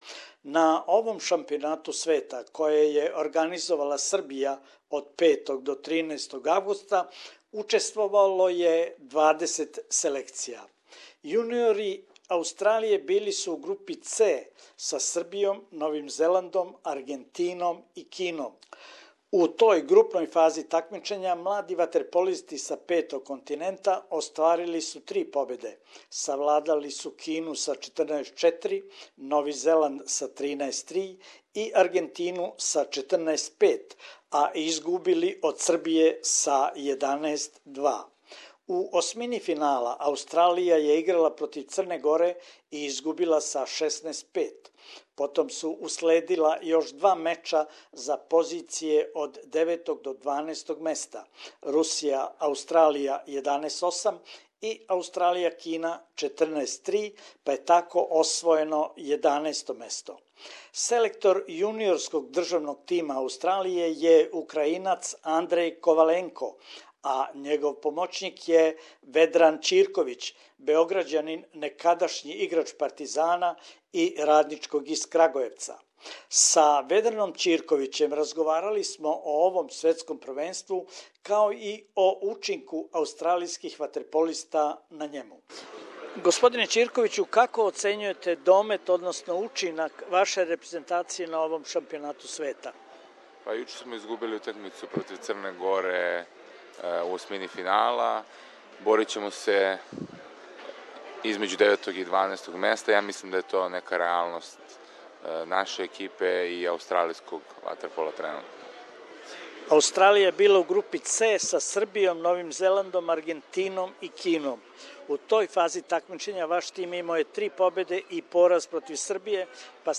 на маргинама светског јуниорског првенства у ватерполу